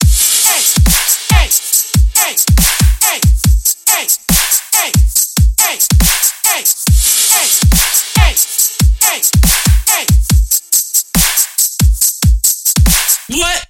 阿拉伯语Dubstep大鼓
标签： 140 bpm Dubstep Loops Drum Loops 2.31 MB wav Key : Unknown
声道立体声